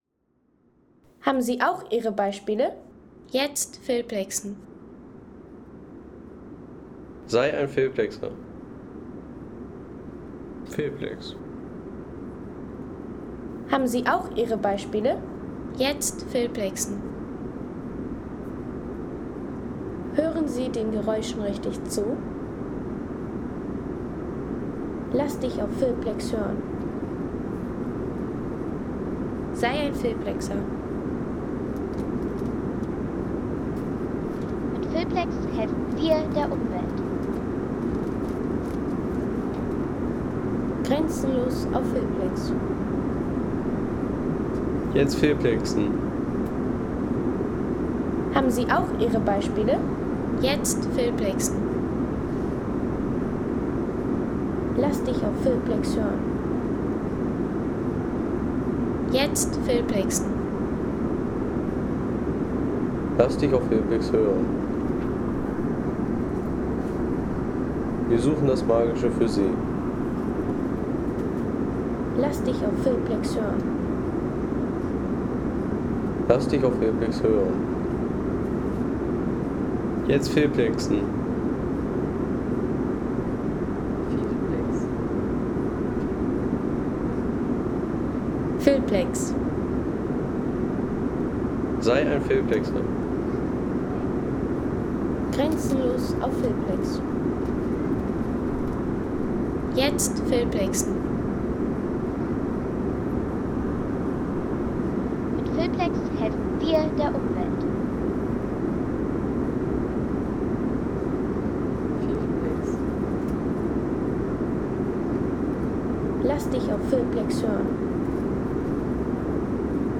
Der Klang einer Flugmaschine
Der Klang einer Flugmaschine während des Fluges und der Landung.